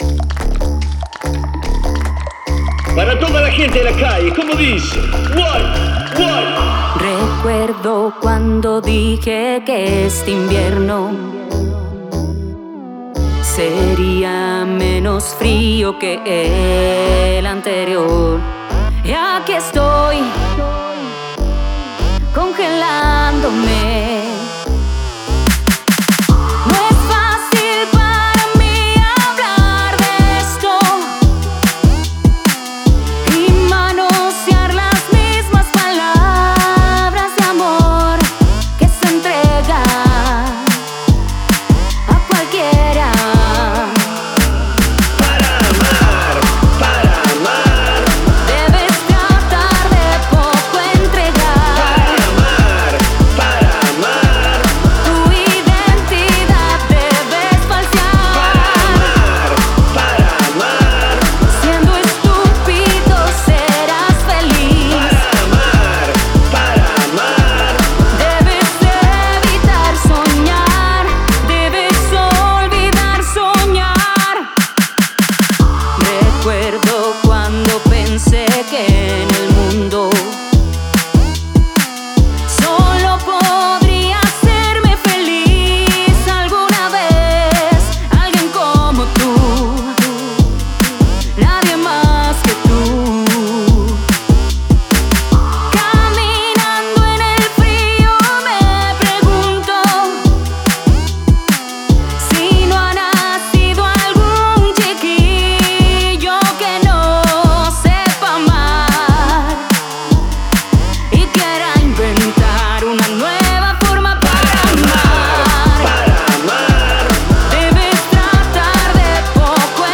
Canción grabada en el Estudio de Grabación de San Miguel